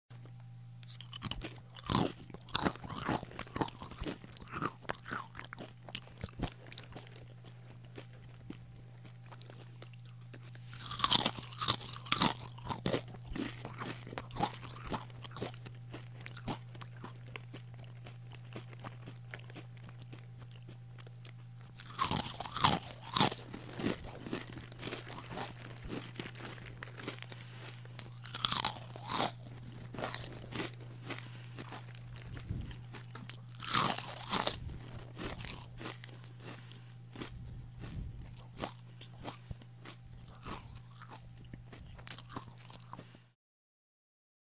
جلوه های صوتی
دانلود صدای خوردن چیپس 1 از ساعد نیوز با لینک مستقیم و کیفیت بالا